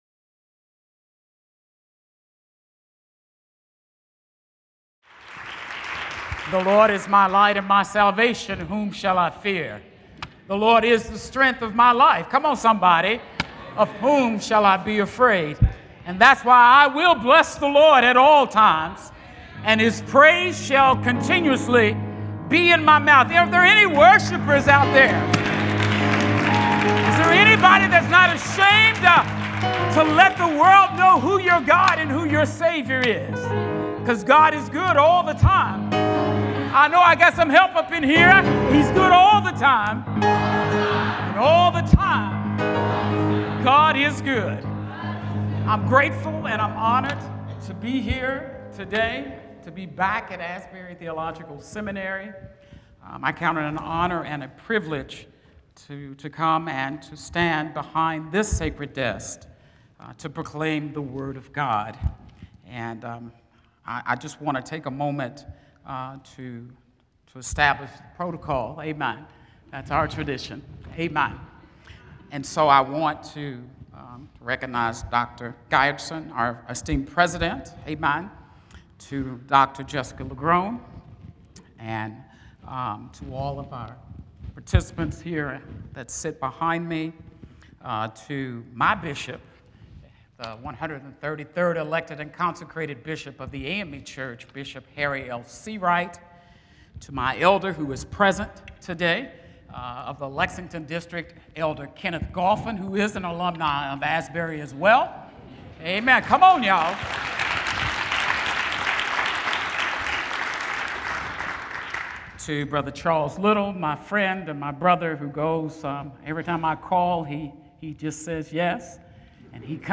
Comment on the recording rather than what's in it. The following service took place on Tuesday, February 18, 2025.